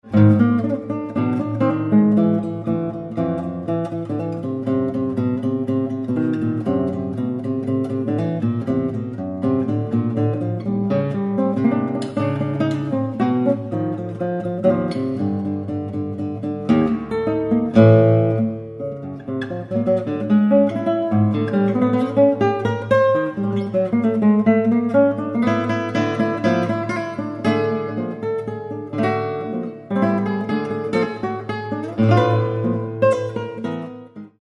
Classical Composer
Classical Guitar